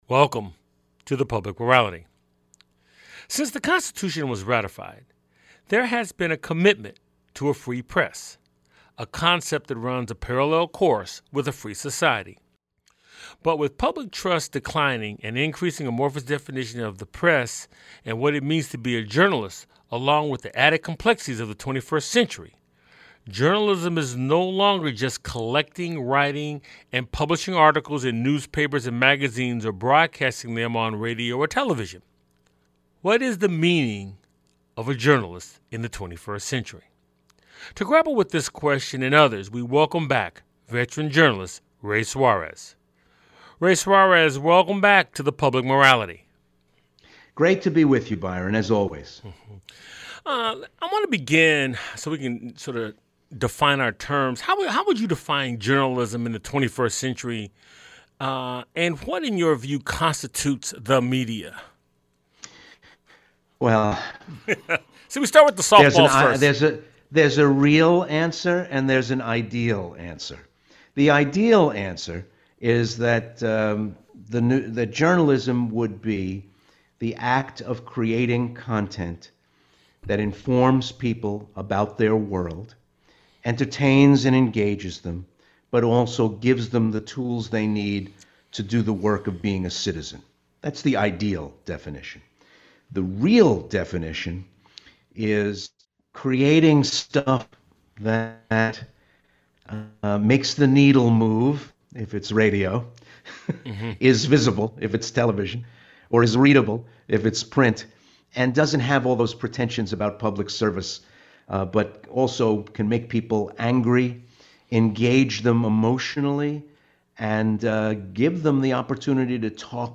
The guest on this show is Veteran Journalist Ray Suarez. It's a weekly conversation with guest scholars, artists, activists, scientists, philosophers, and newsmakers who focus on the Declaration of Independence, the Constitution and the Emancipation Proclamation as its backdrop for dialogue on issues important to our lives.